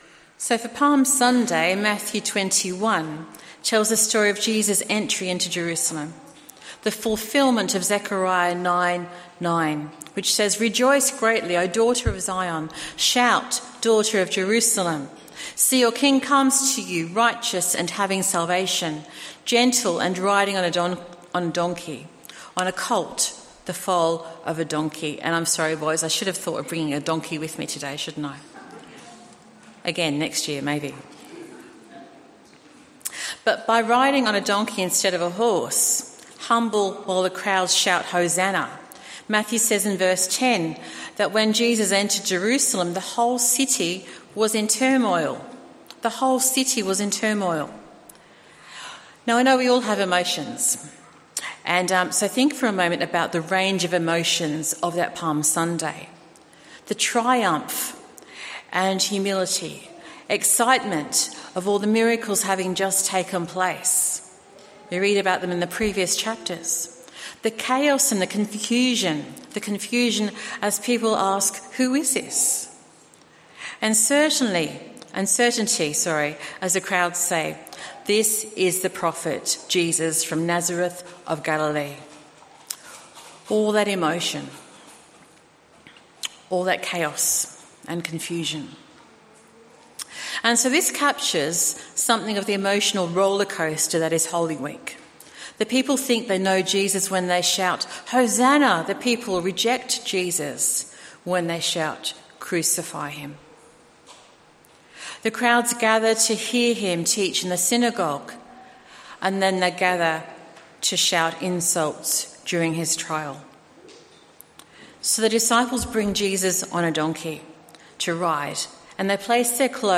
Sermon from the 10AM meeting of Newcastle Worship & Community Centre of The Salvation Army on 09/04/2017. The meeting included readings of Psalm 118 and Matthew 21:1-11.